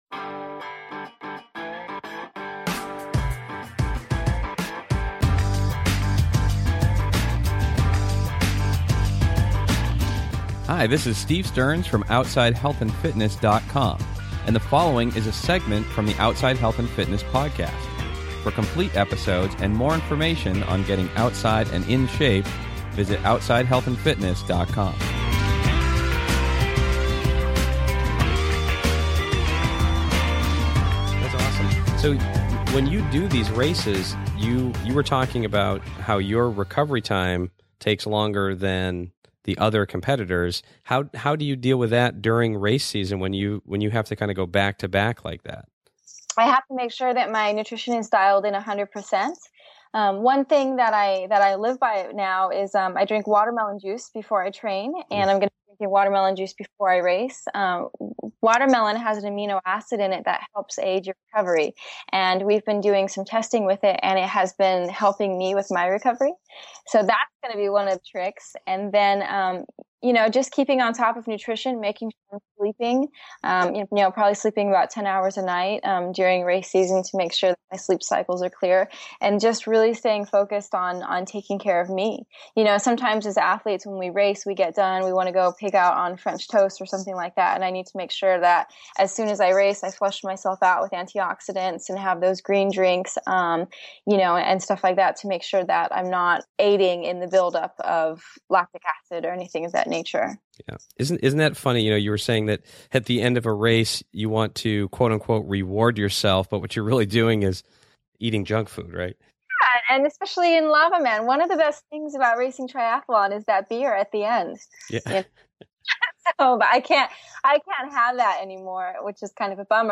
In this audio brief of my upcoming interview